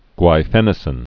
(gwī-fĕnə-sĭn)